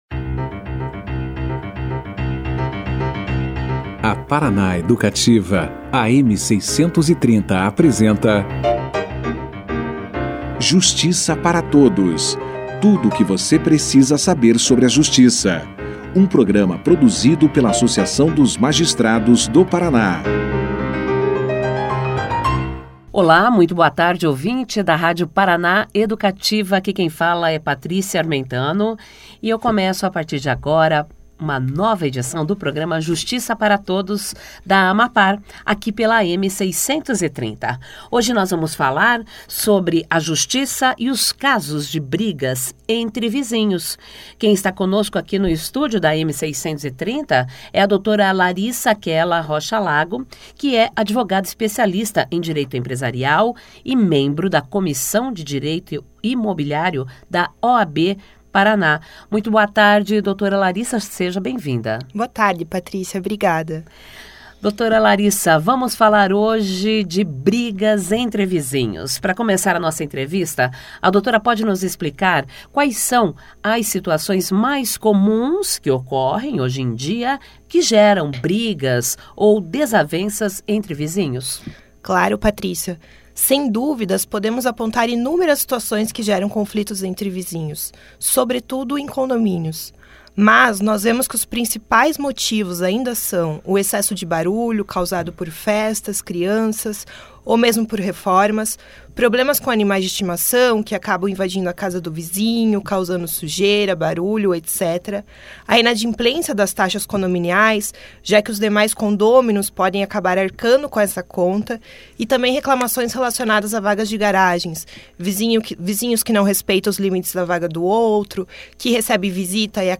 Na segunda-feira (24), os temas debatidos pelo programa de rádio da AMAPAR, Justiça para Todos, foi a justiça e os casos de briga entre vizinhos.